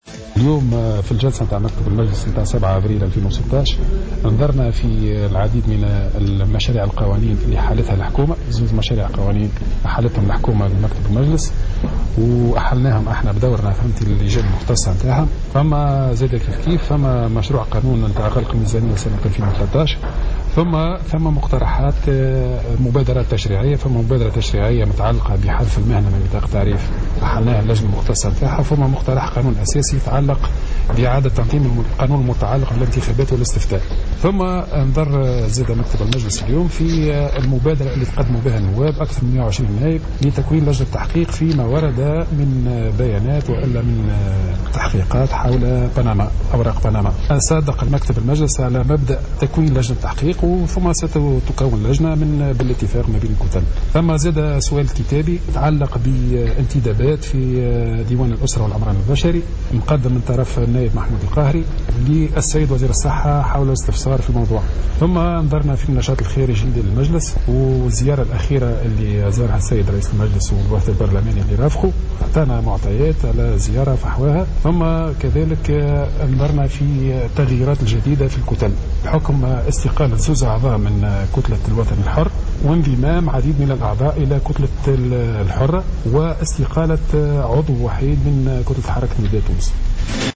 في تصريح لمراسل "الجوهرة أف أم" اليوم